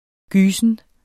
Udtale [ ˈgyːsən ]